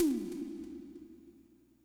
percussion.wav